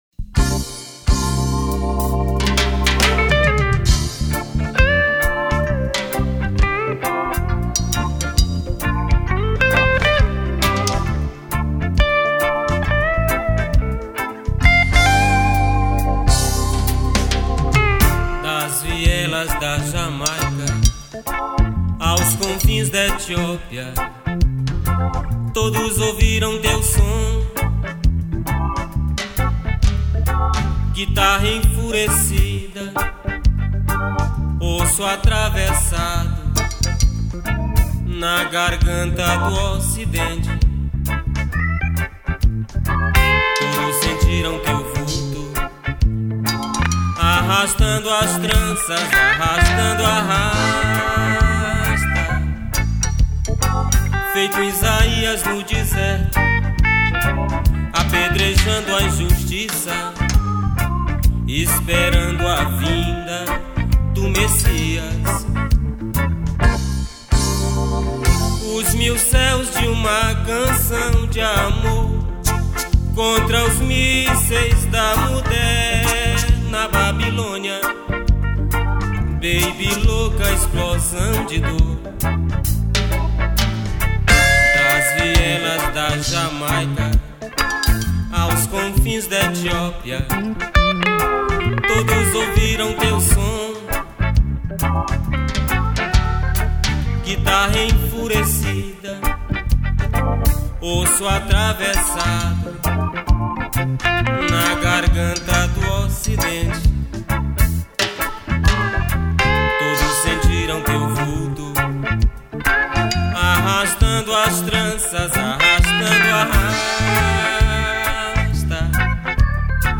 Guitarra
Teclados
Bateria
Percussão